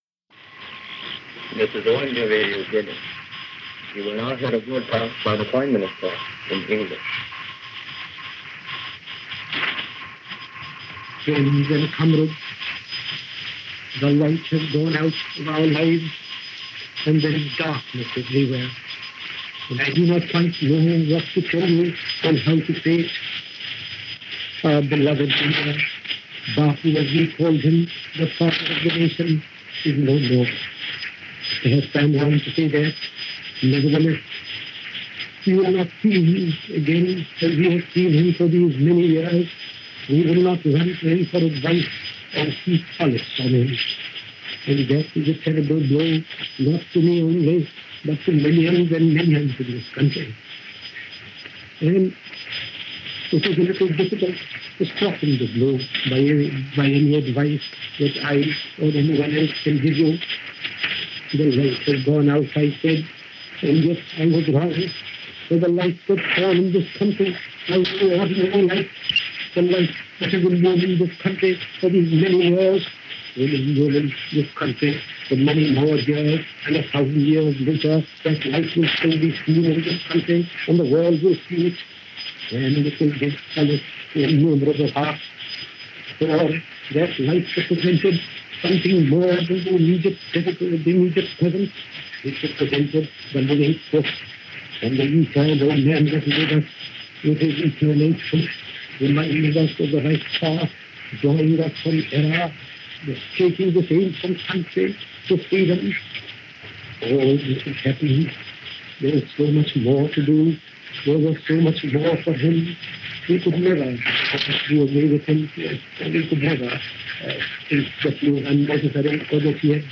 The Death Of Gandhi - January 30, 1948 - Past Daily After Hours Reference Room - broadcast live by All-India Radio shortwave service.
This was a shortwave broadcast, beamed throughout the world by All-India Radio from their studios in New Delhi. But because it’s shortwave, the sound is dim and distant and very difficult to make out at times because of the nature of technology at the time.
Nehru-Announces-Gandhi-death-1.mp3